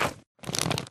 jump1.ogg